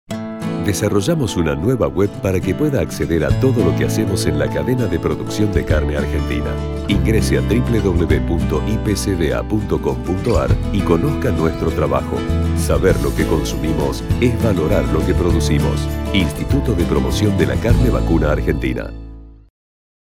Campaña 2007 en los Medios Nota Diario Clarín Nota Diario El Cronista Comercial Nota Revista El Federal 1 Nota Revista El Federal 2 Nota Diario La Nación Entrevista Radial Revista Supercampo 1 Revista Supercampo 2